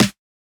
Index of /99Sounds Music Loops/Drum Oneshots/Twilight - Dance Drum Kit/Snares